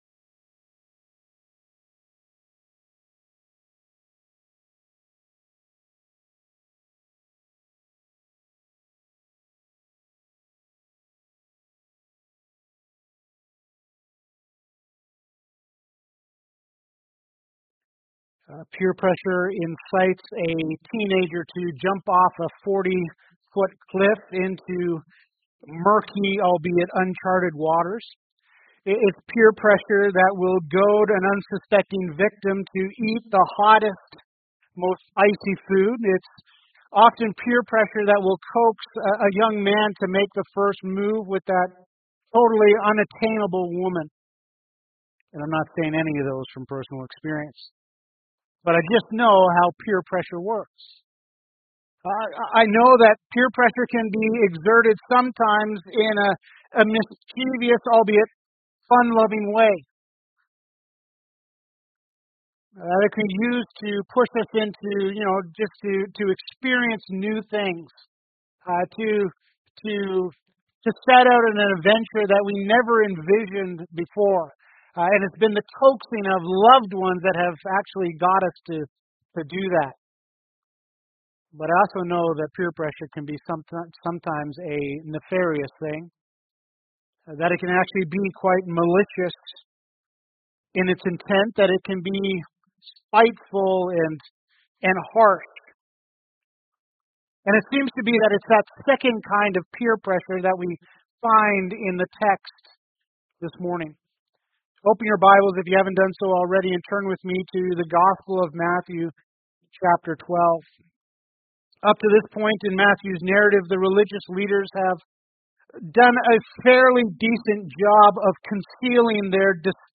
Sermons - Grace Bible Fellowship